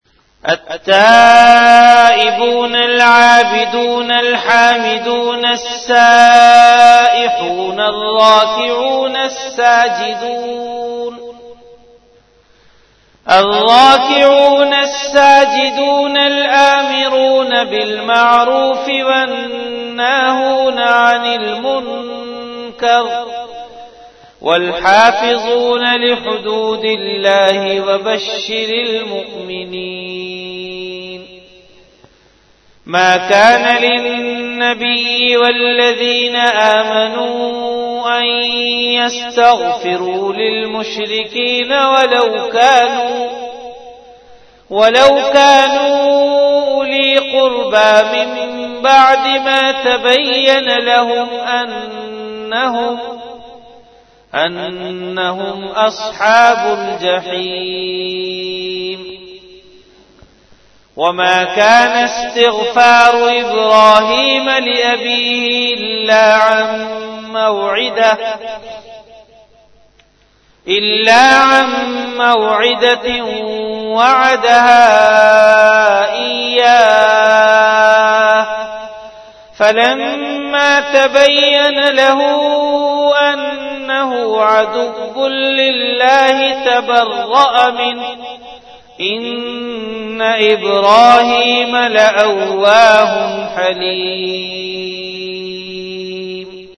CategoryTilawat
VenueJamia Masjid Bait-ul-Mukkaram, Karachi
Event / TimeAfter Isha Prayer